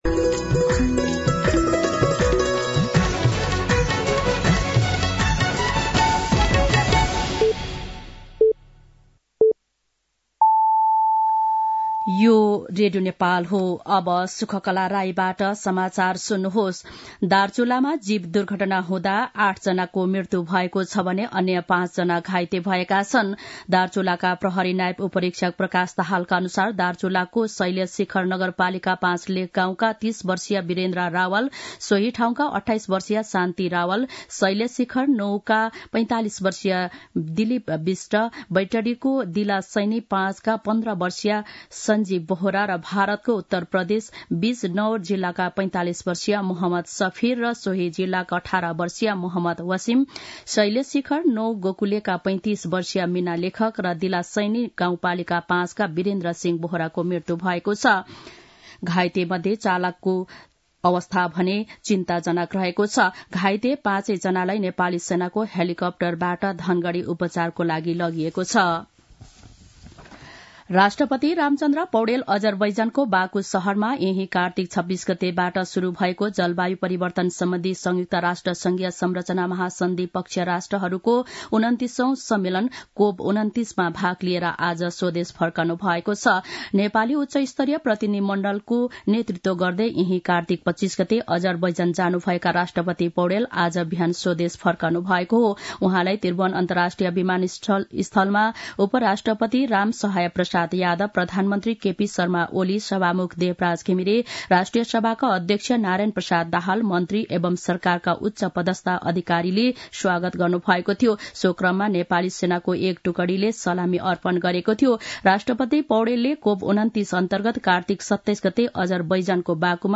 दिउँसो १ बजेको नेपाली समाचार : १ मंसिर , २०८१
1-pm-Nepali-News-1.mp3